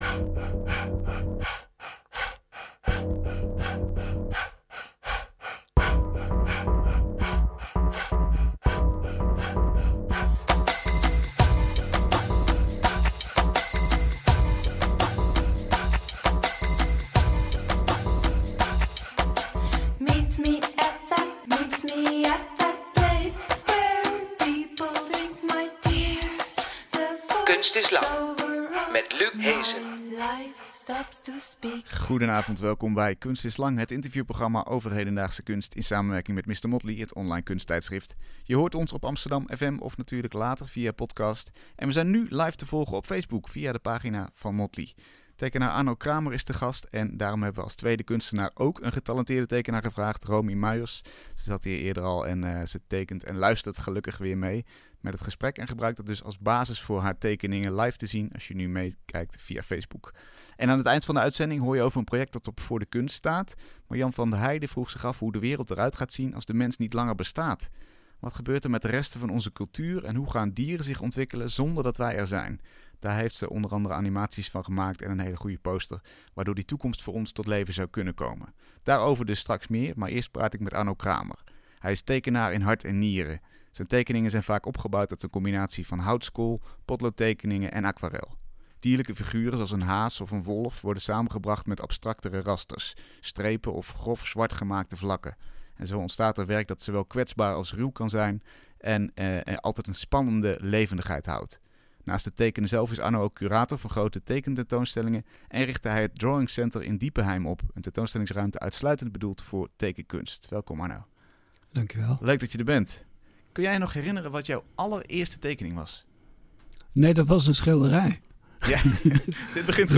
Een gesprek over waarom tekenen zo'n magische kunstvorm is.